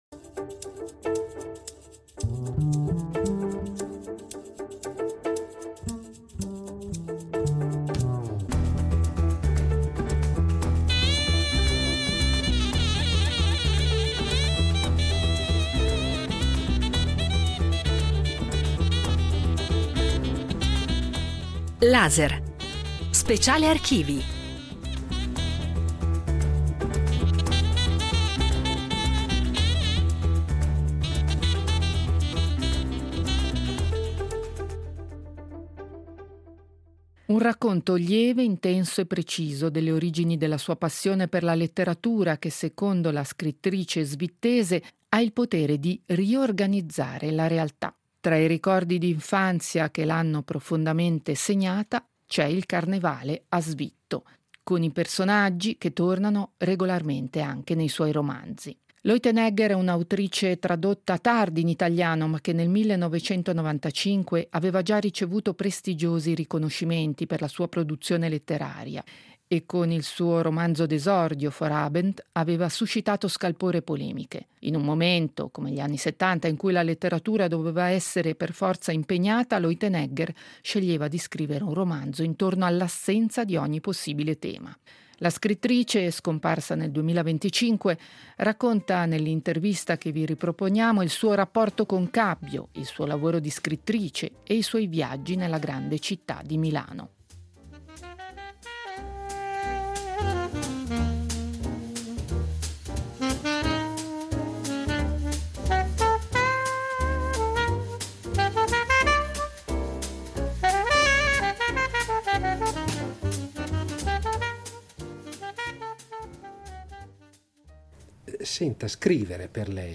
Intervista a Gertrud Leutenegger
Vi riproponiamo un’intervista radiofonica realizzata nel 1995, tratta dagli archivi RSI , in cui Gertrud Leutenegger si racconta, parlando della sua vita a Cabbio, dei ricordi d’infanzia che più l’hanno segnata e del lavoro solitario della scrittura.